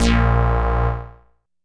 synth note03.wav